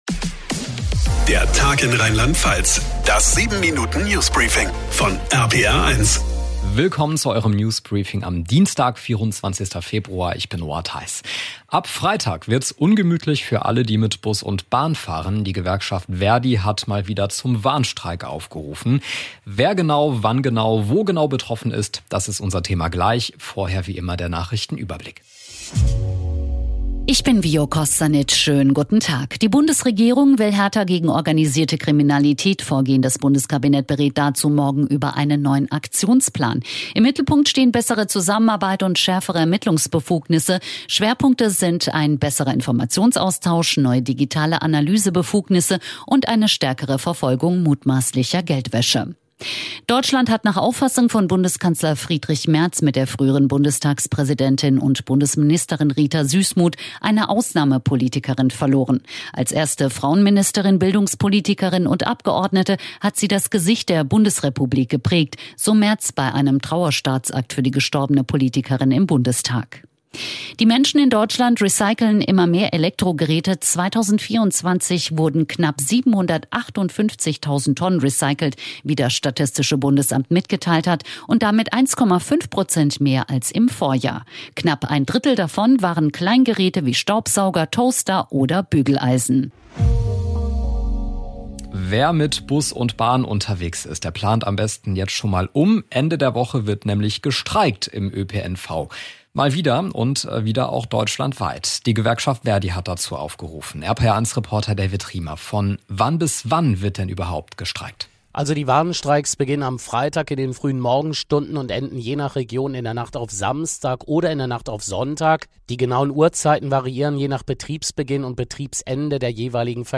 Das 7-Minuten News Briefing von RPR1.